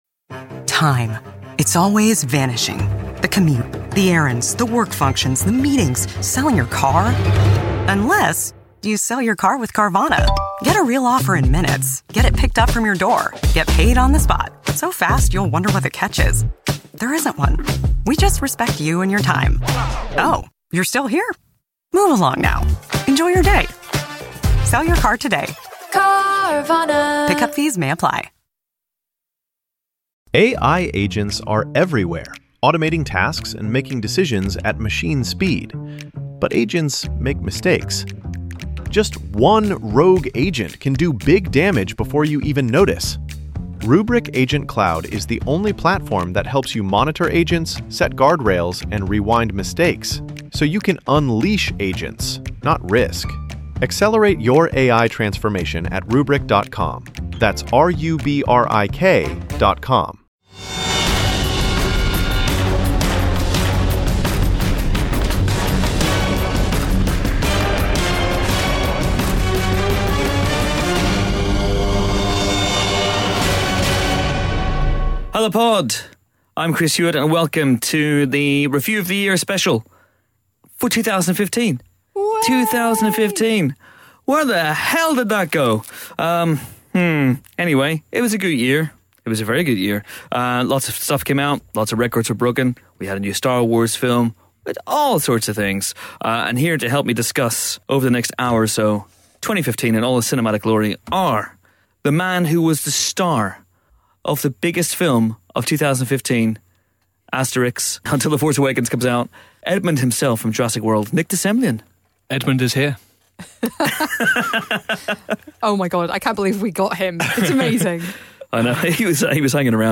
Review Of The Year 2015 The Empire Film Podcast Bauer Media Tv & Film, Film Reviews 4.6 • 2.7K Ratings 🗓 27 December 2015 ⏱ 57 minutes 🔗 Recording | iTunes | RSS 🧾 Download transcript Summary With 2015 drawing to a close, we assembled four Empire writers into the pod booth to reflect on the year's highs and lows in movieland.